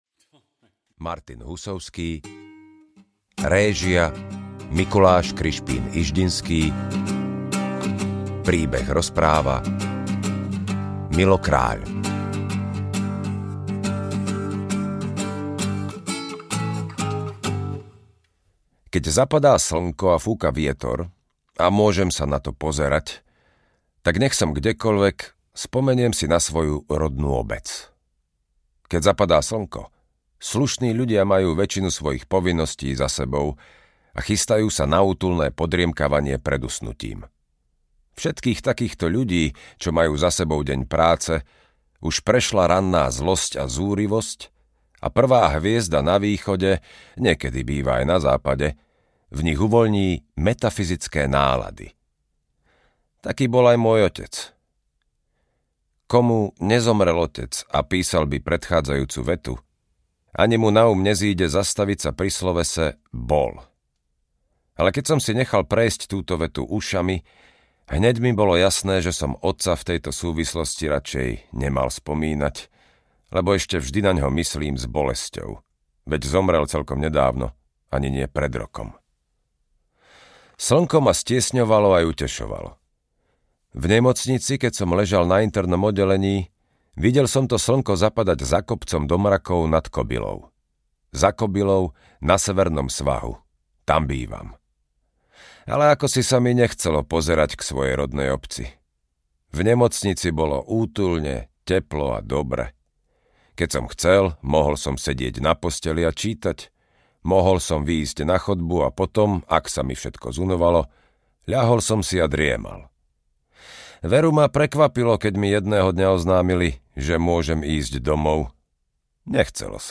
Rozum audiokniha
Ukázka z knihy